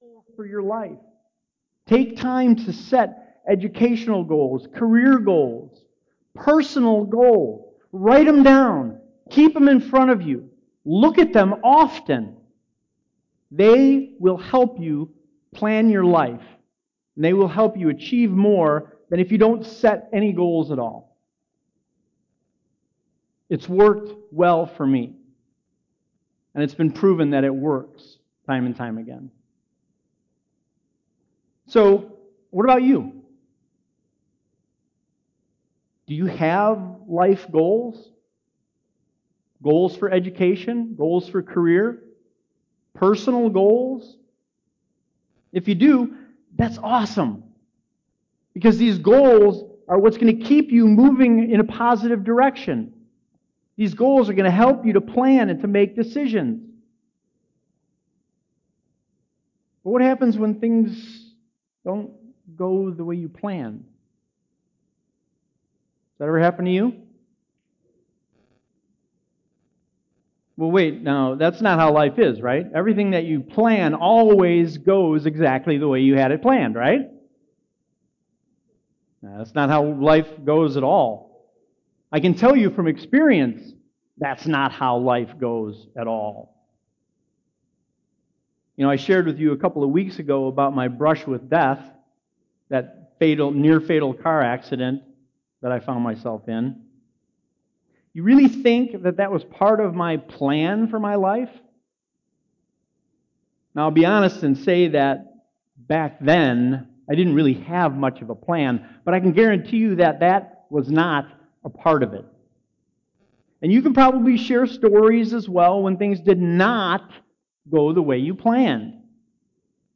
Hope Community Church of Lowell's Sunday sermon audio's online for your convience.